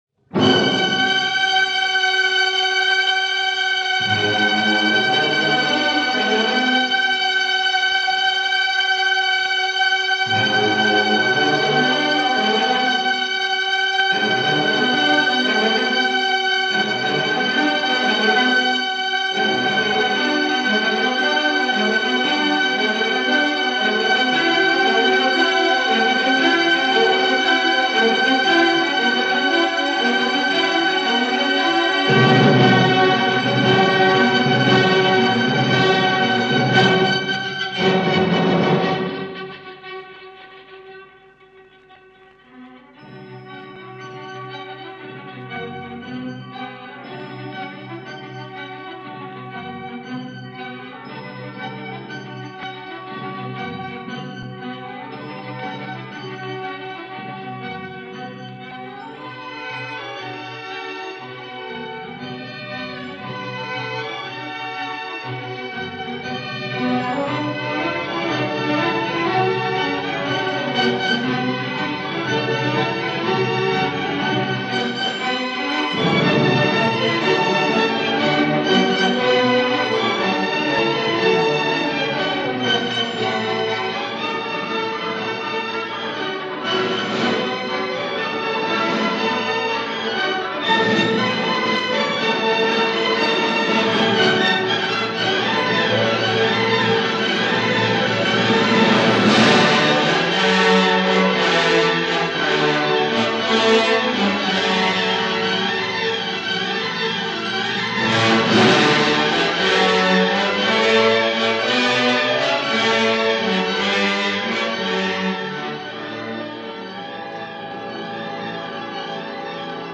Impressions d’Italie was a suite of orchestral pieces which served as the basis for Louise.
This broadcast performance of the Napoli movement is guessed at being from 1952 (although I am sure I will get the correct date shortly) features the French National Radio Orchestra, conducted by the legendary Eugène Bigot , himself a frequent performer of Charpentier’s works.
Sometimes it’s frustrating because the sound quality isn’t always as good as it could be, or the discs themselves haven’t aged particularly well (16″ transcription discs not highly regarded by radio stations at the time), but inside those grooves lay some fascinating documents and amazing discoveries.